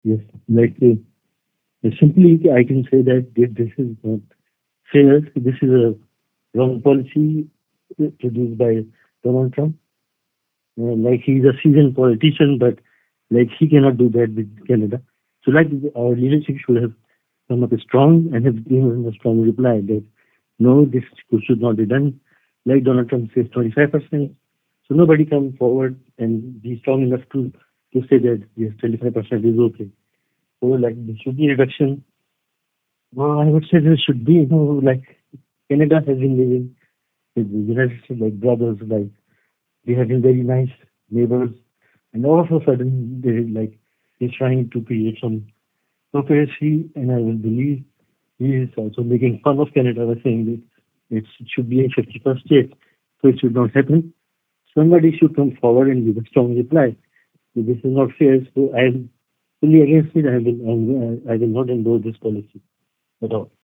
He was available by phone for this interview.